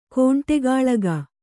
♪ kōṇṭegāḷaga